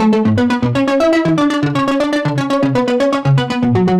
Index of /musicradar/french-house-chillout-samples/120bpm/Instruments
FHC_Arp A_120-A.wav